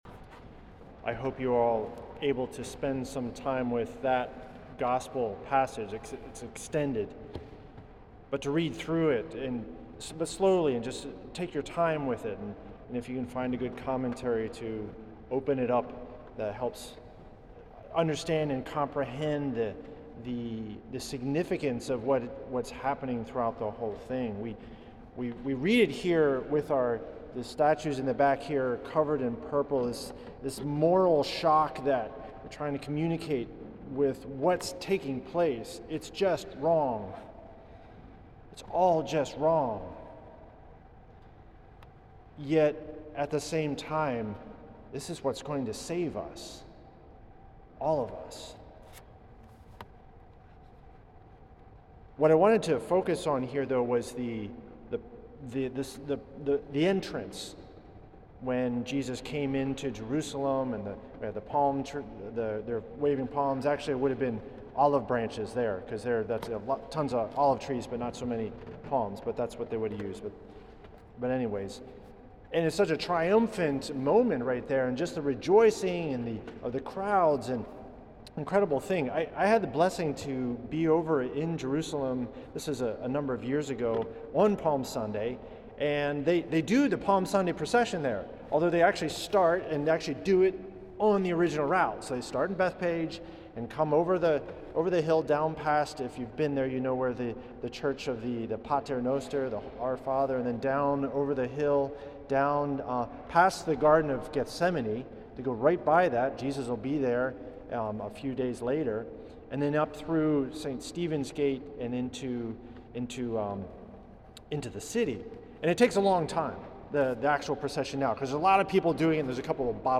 Homily
for Palm Sunday, March 24th at St. Patrick’s Old Cathedral in NYC.